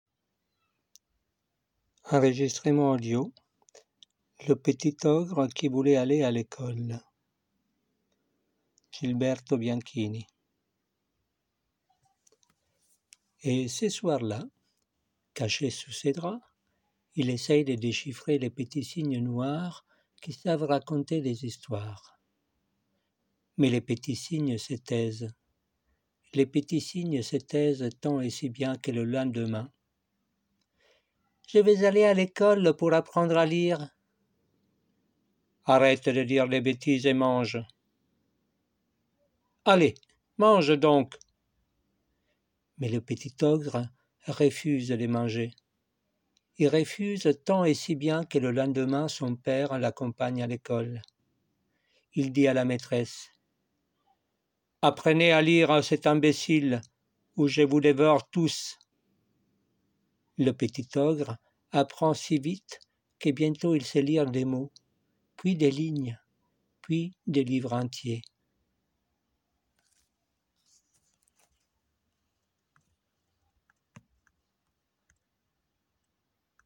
Lecture extrait : Le petit ogre qui voulait aller à l'école
- Ténor